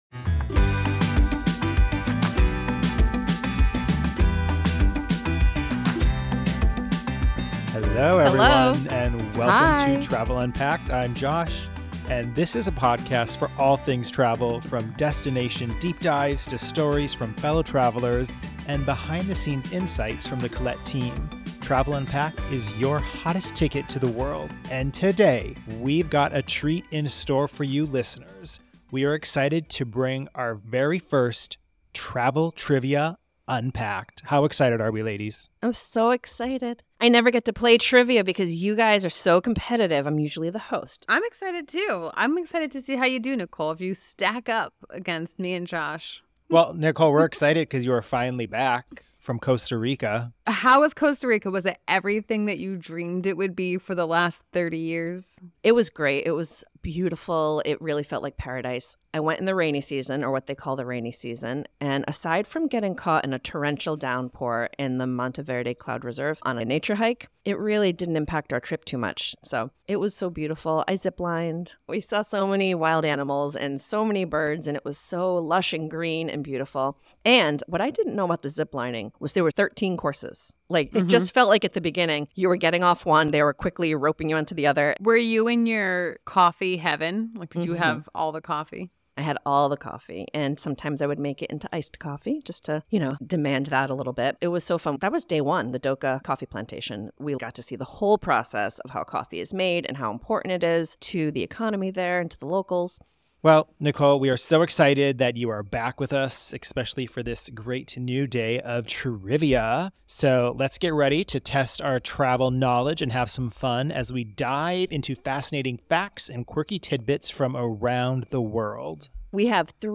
Get ready for three action-packed rounds where they test their global knowledge, tackle iconic sounds from around the world, duel over Nordic and Scandinavian facts, and dive into bizarre and quirky travel trivia.